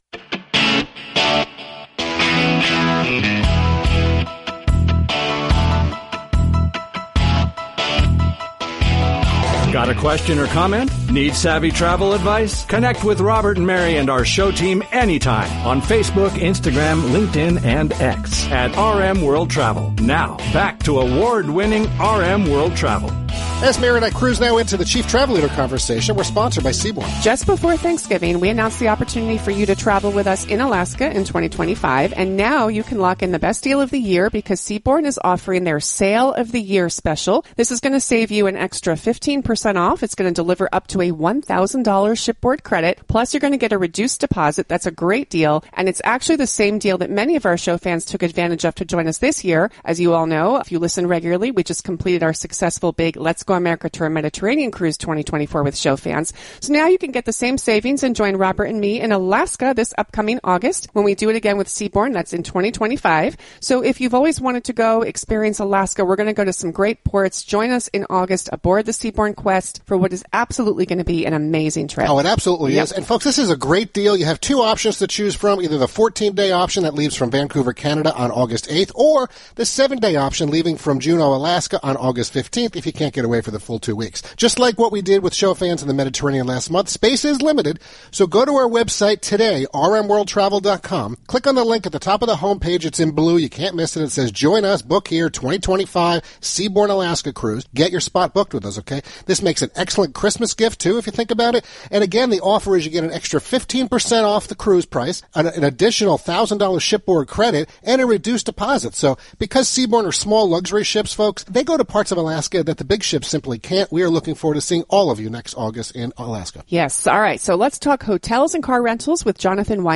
That changed during the live national broadcast of America’s #1 Travel Radio Show on December 7th 2024, as we connected with him to discuss some of the current realities and happenings within the car rental and hotel sectors of travel, we talked about our preferred brands/operators, engaged ways his firm is benefiting travelers plus how they compare to others out there, and more.